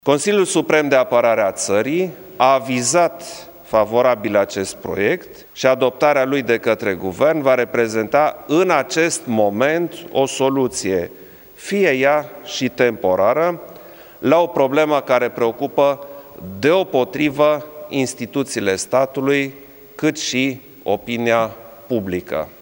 Anunţul a fost făcut de preşedintele Klaus Iohannis la finalul ședinței CSAT: